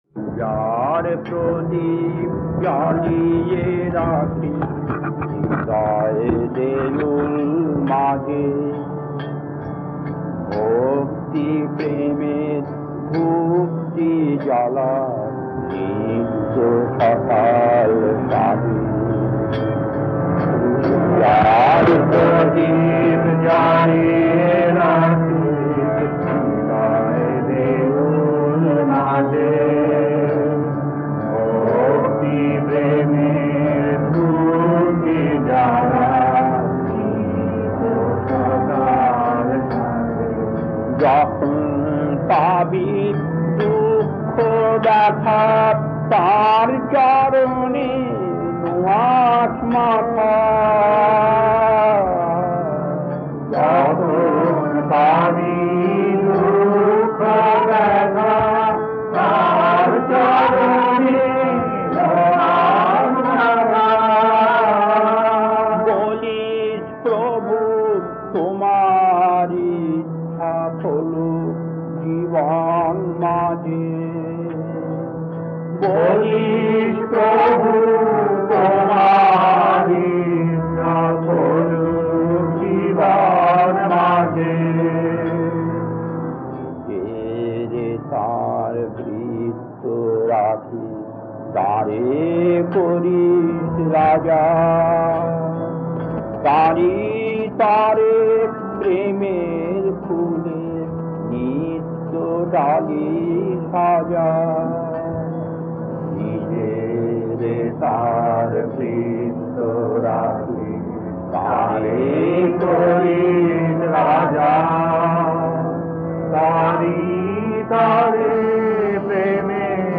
Kirtan D3-2 1.